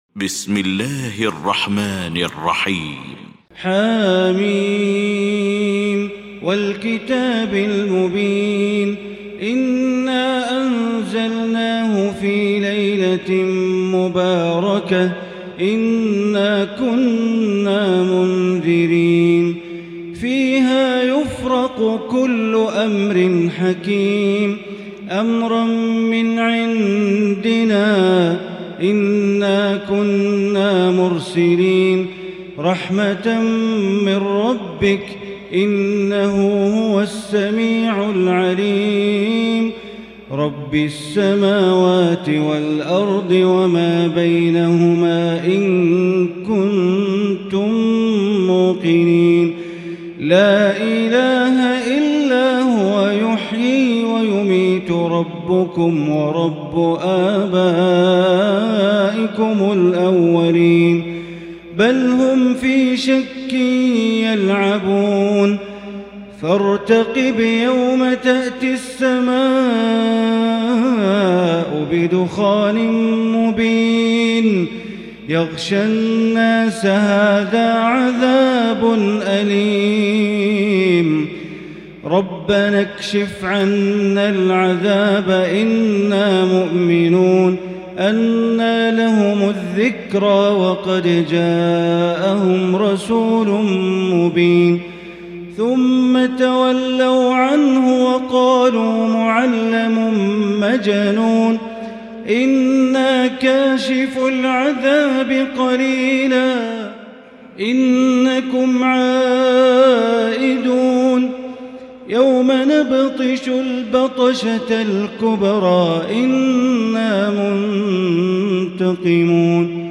المكان: المسجد الحرام الشيخ: معالي الشيخ أ.د. بندر بليلة معالي الشيخ أ.د. بندر بليلة الدخان